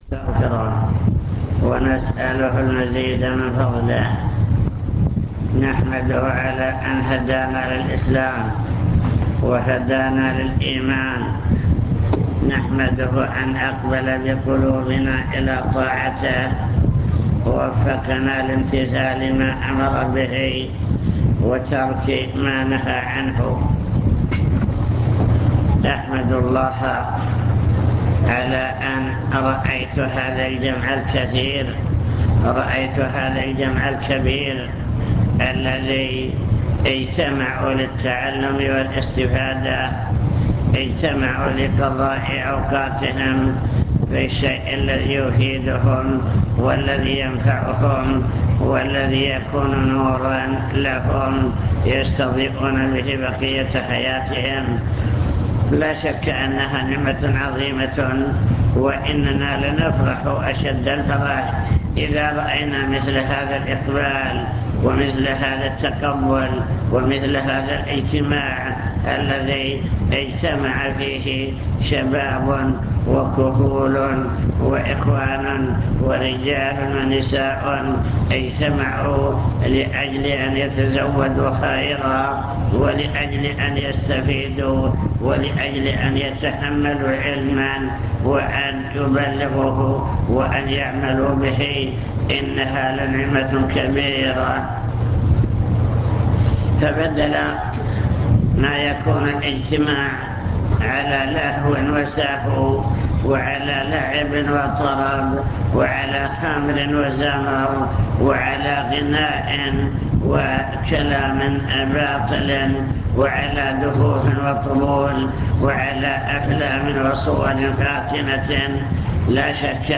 المكتبة الصوتية  تسجيلات - لقاءات  لقاء مفتوح بالمخيم التوعوي للشباب